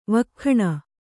♪ vakkhaṇa